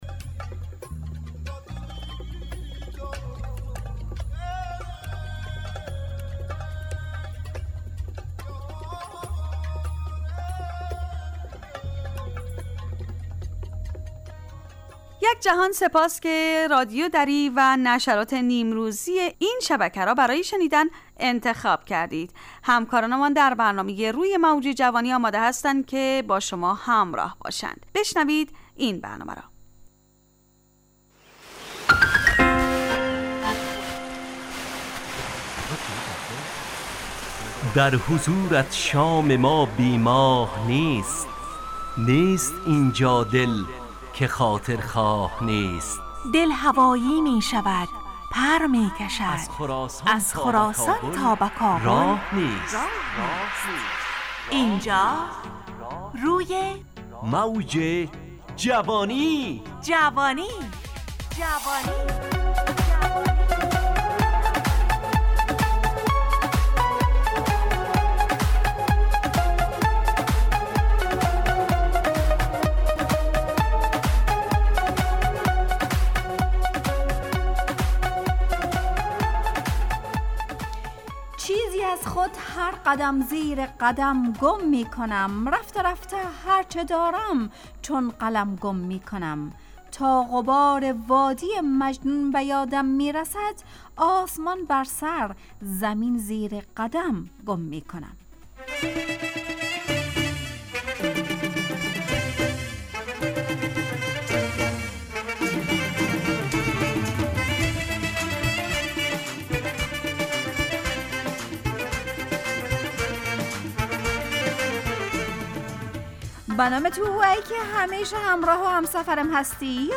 روی موج جوانی، برنامه شادو عصرانه رادیودری. از شنبه تا پنجشنبه ازساعت 17 الی 17:55 طرح موضوعات روز، وآگاهی دهی برای جوانان، و.....بخشهای روزانه جوان پسند....
همراه با ترانه و موسیقی .